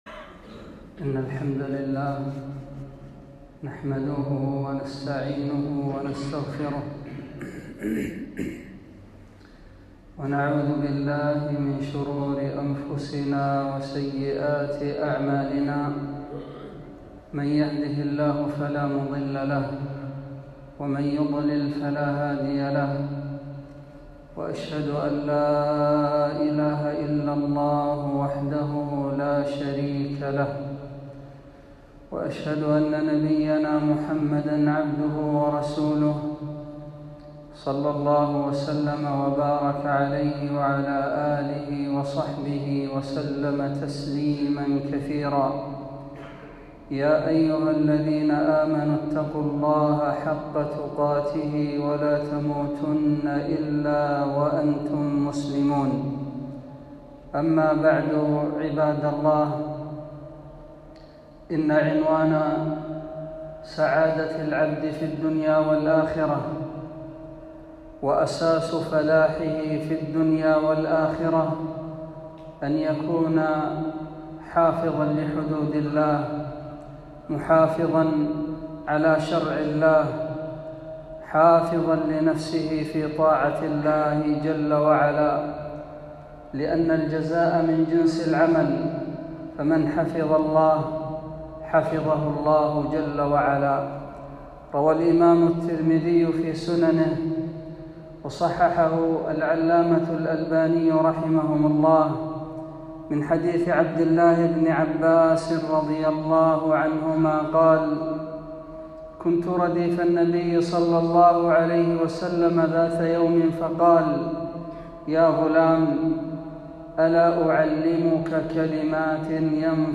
خطبة - احفظ الله يحفظك